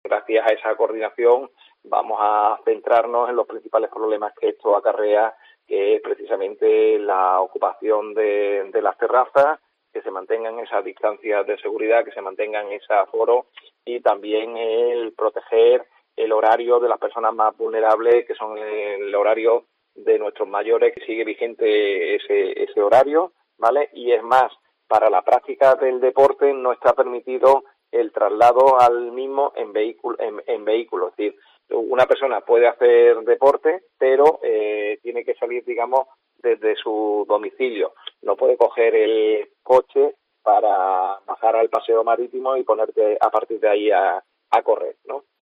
El concejal de Seguridad del Ayuntamiento de Málaga, Avelino Barrionuevo, detalla en COPE las novedades de la fase 1
Audio del concejal de Movilidad, Avelino Barrionuevo en COPE Málaga.